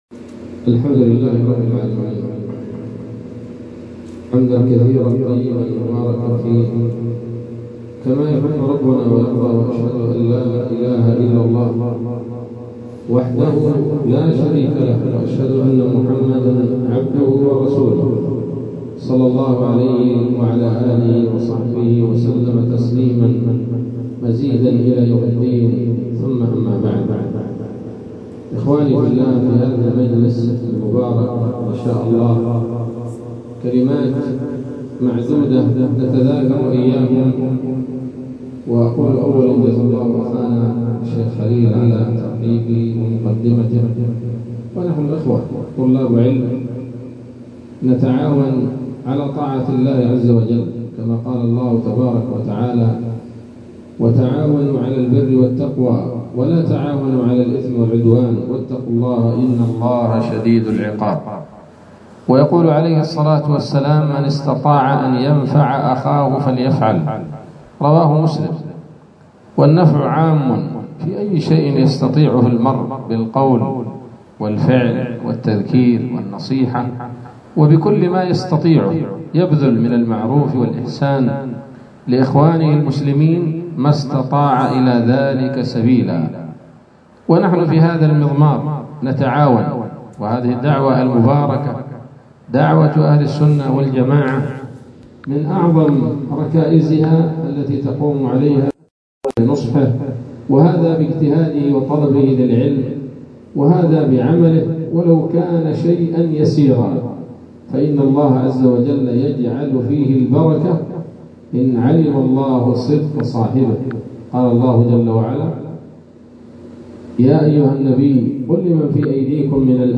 محاضرة قيمة بعنوان: (( ‌من عوامل الثبات في مواجهة الباطل )) عصر السبت 23 ربيع الآخر 1446هـ، بالمدينة النبوية - المملكة العربية السعودية